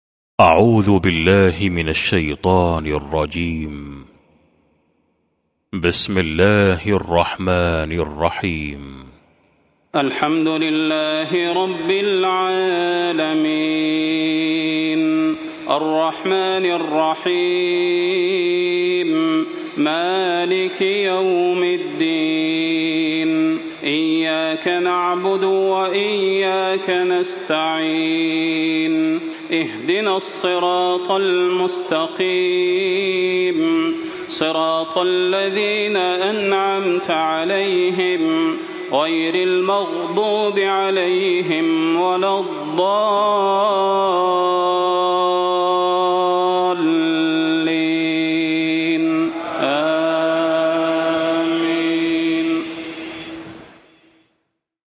المكان: المسجد النبوي الشيخ: فضيلة الشيخ د. صلاح بن محمد البدير فضيلة الشيخ د. صلاح بن محمد البدير الفاتحة